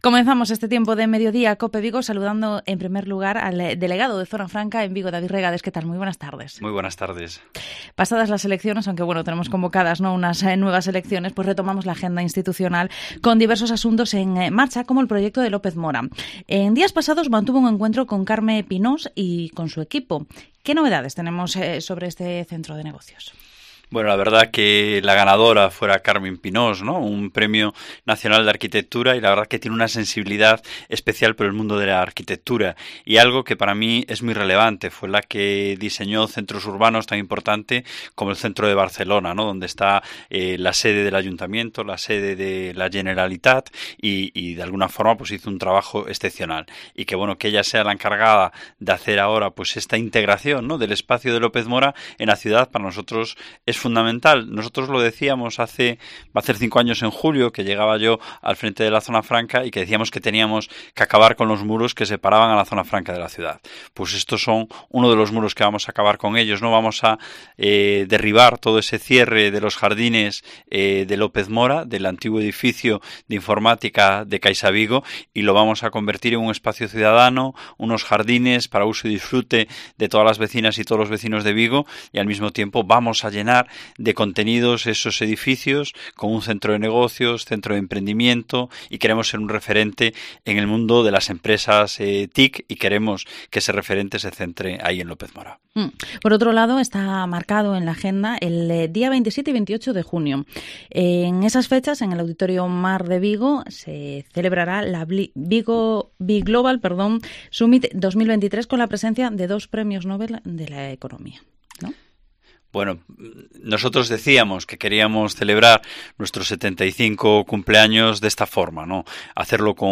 Entrevista al Delegado de Zona Franca en Vigo, David Regades